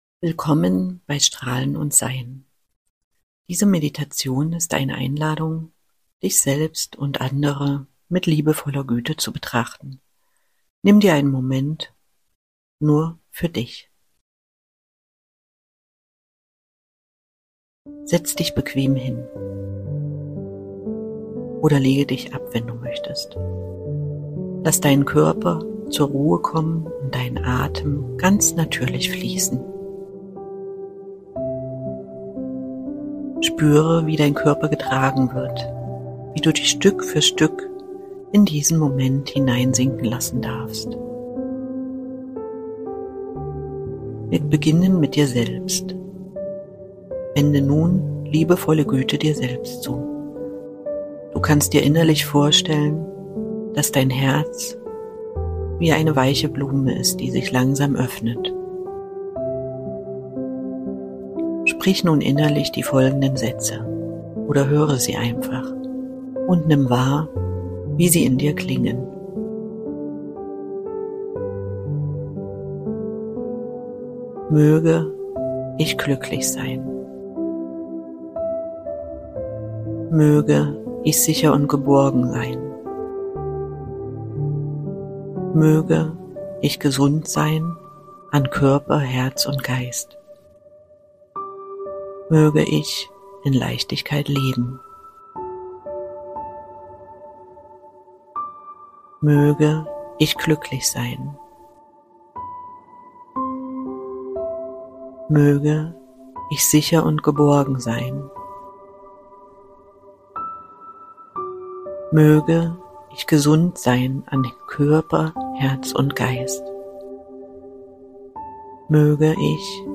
Liebevolle-Güte-Meditation